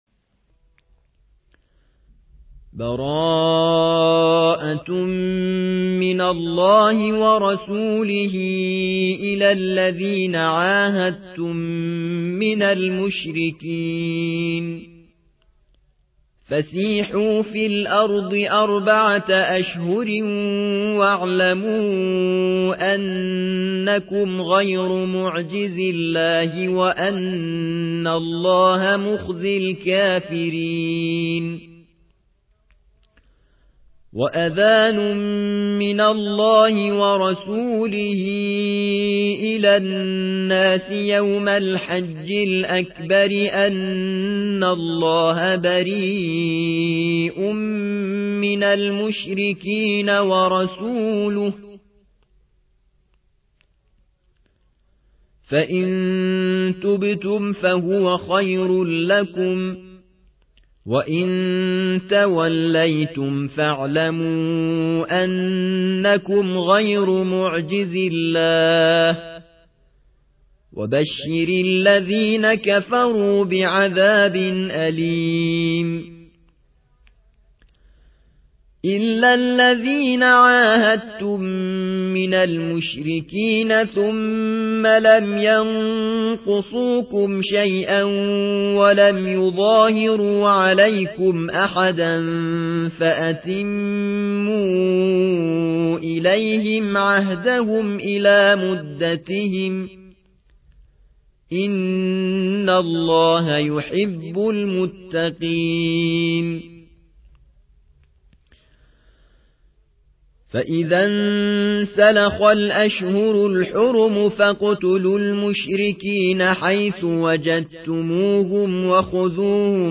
9. سورة التوبة / القارئ